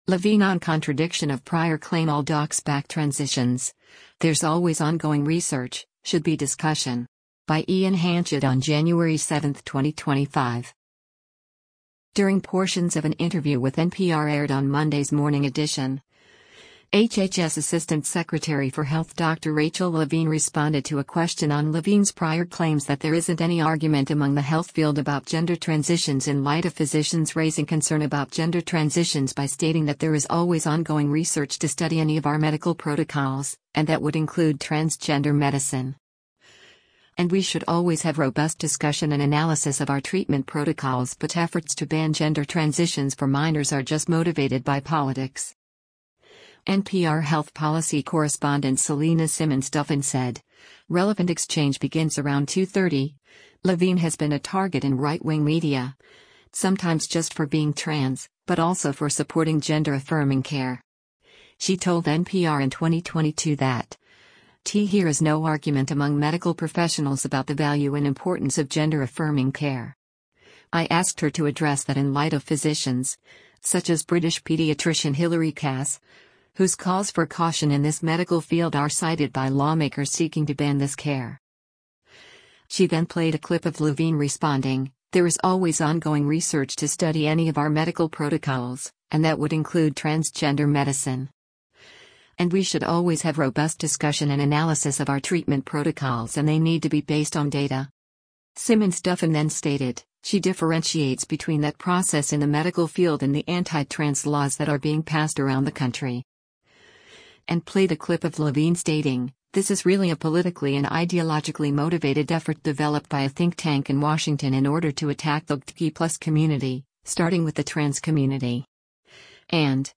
During portions of an interview with NPR aired on Monday’s “Morning Edition,” HHS Assistant Secretary for Health Dr. Rachel Levine responded to a question on Levine’s prior claims that there isn’t any argument among the health field about gender transitions in light of physicians raising concern about gender transitions by stating that “There is always ongoing research to study any of our medical protocols, and that would include transgender medicine. And we should always have robust discussion and analysis of our treatment protocols” but efforts to ban gender transitions for minors are just motivated by politics.